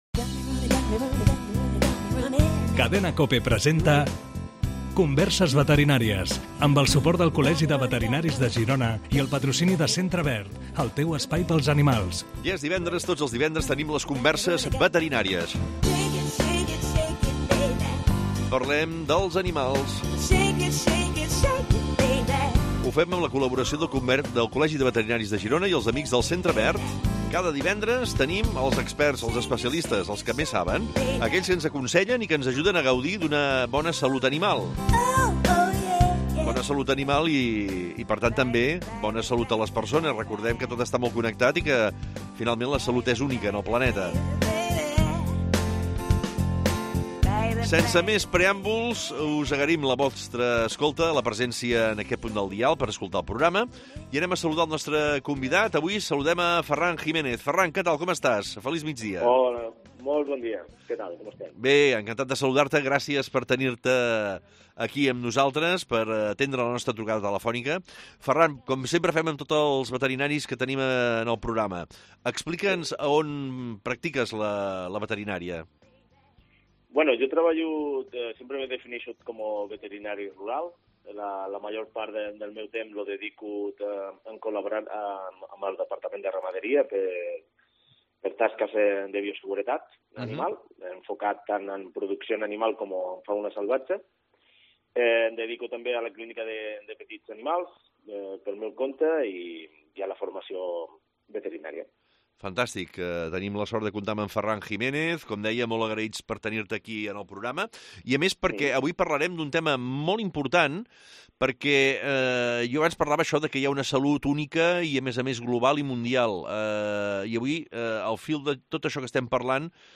Es contesta per ràdio a les preguntes de propietaris de gossos i gats.